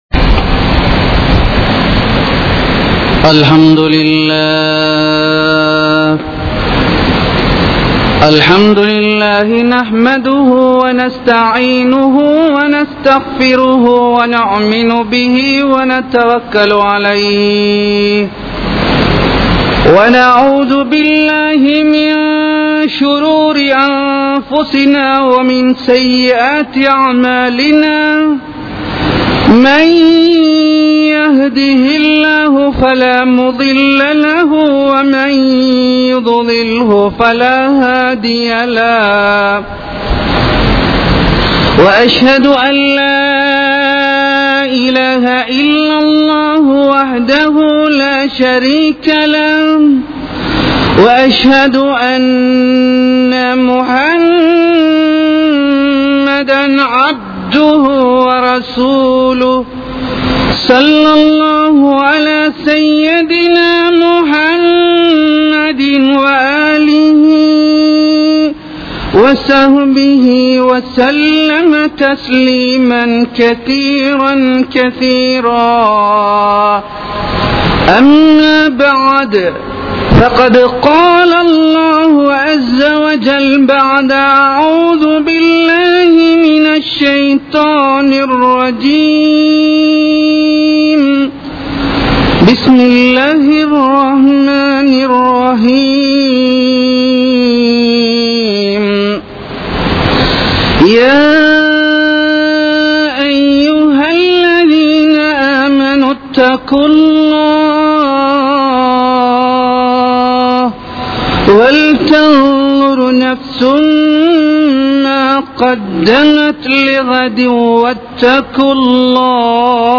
Ahlaaq | Audio Bayans | All Ceylon Muslim Youth Community | Addalaichenai